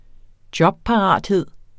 Udtale [ ˈdjʌbpɑˌʁɑˀdˌheðˀ ]